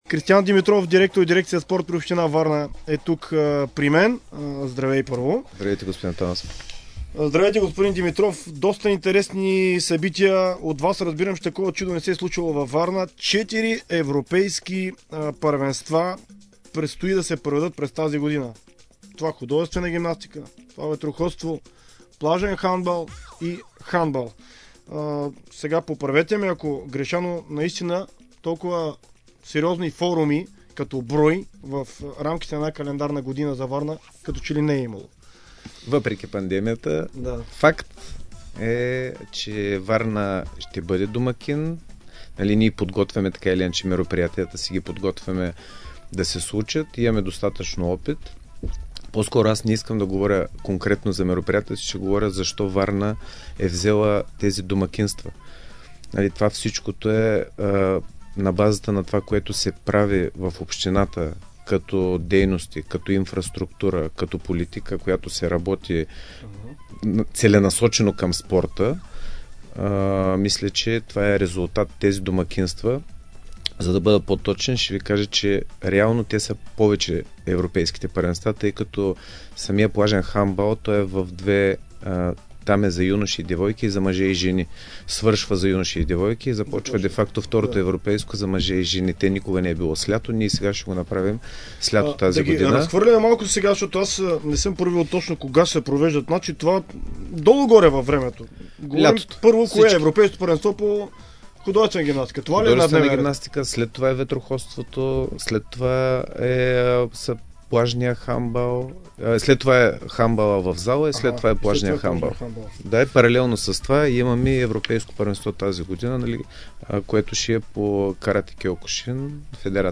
бе гост в спортното шоу на Дарик Североизток тази сутрин.